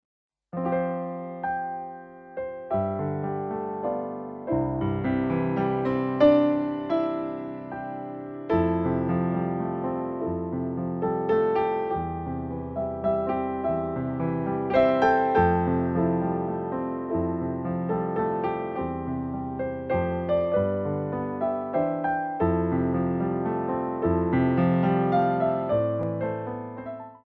Show Tunes for Ballet Class
Ports de Bras / Révérance
3/4 (8x8) + (2x8)